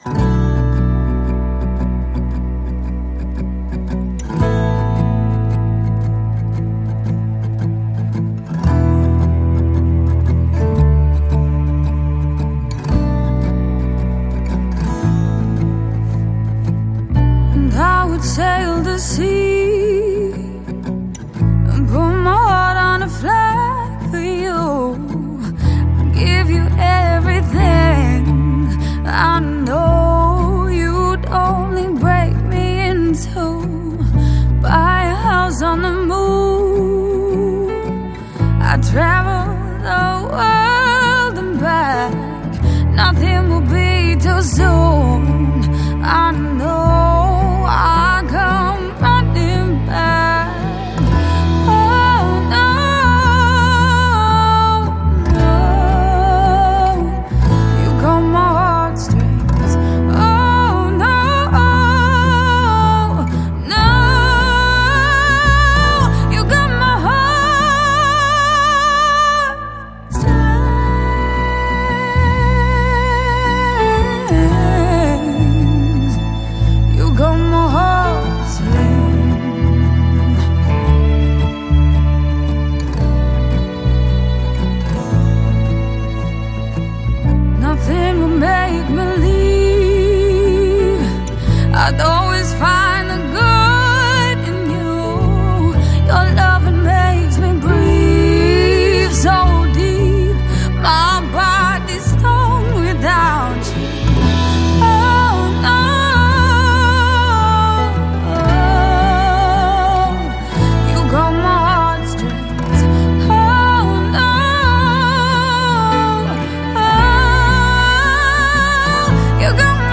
Soul singer-songwriter